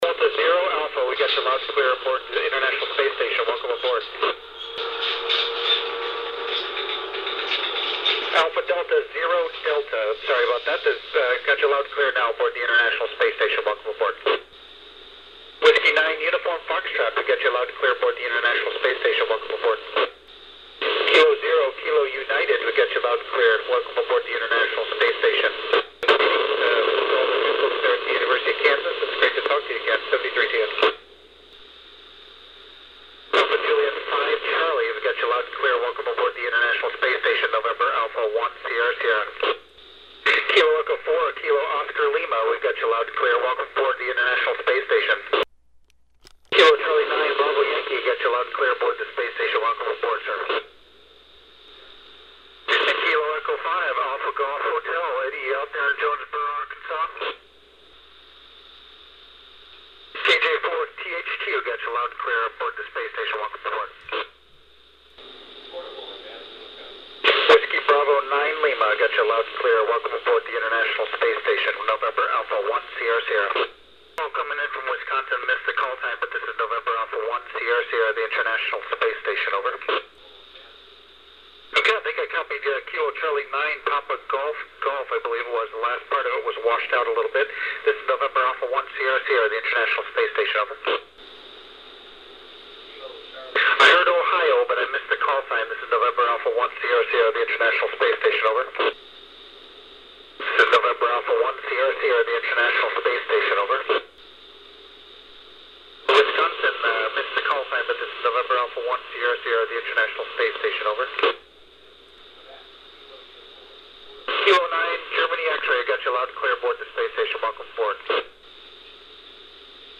Colonel Wheelock makes reference to switching channels, and returning to "channel one." All received on 145.800 downlink, which makes me wonder if he is purposely switching to simplex for some clearer air.